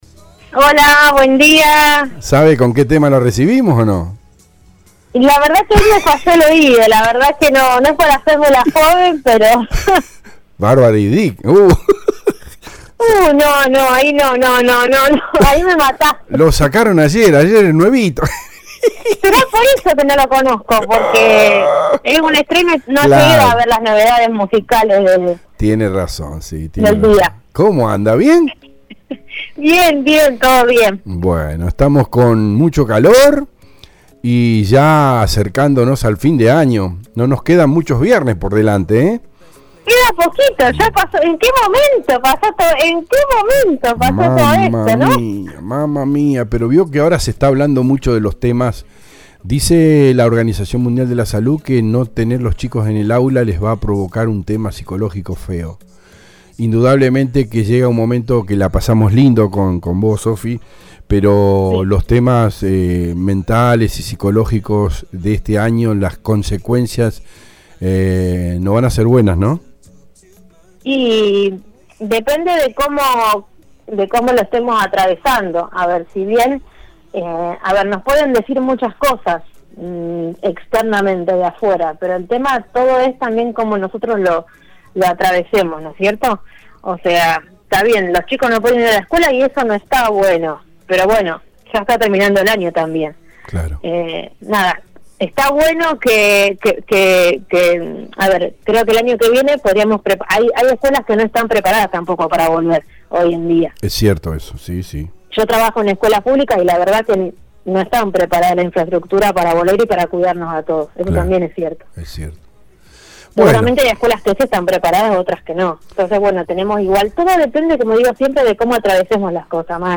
Acompañamos con los audios en vivo.
En la edición Nº 2104, los tres colaboradores del programa de cada viernes, aportaron distintas temáticas, como ser: novedades tecnológicas y tips comerciales por Internet, los tres clásicos consejos psicológicos y hasta, el análisis con opinión política local y nacional. Te invitamos a escuchar los audios de cada una de las conversaciones en radio EL DEBATE.